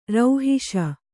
♪ rauhiṣ